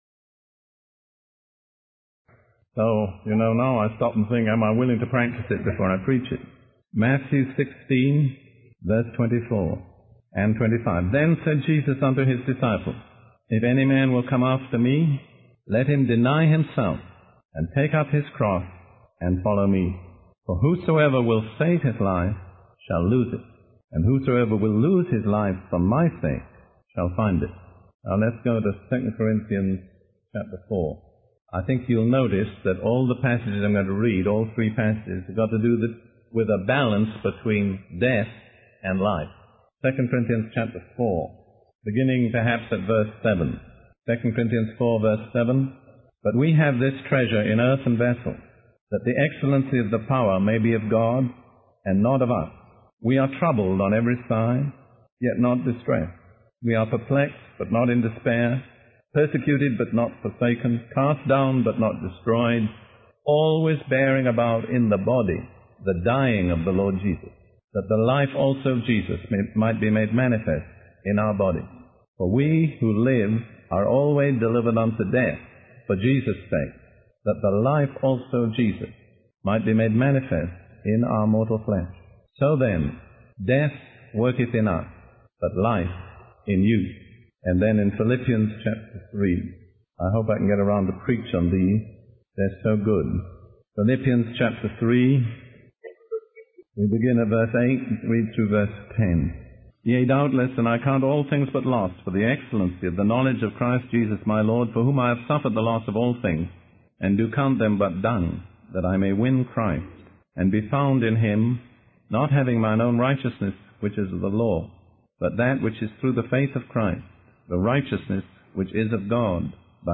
In this sermon, the preacher emphasizes the importance of giving up worldly possessions and desires in order to gain eternal life.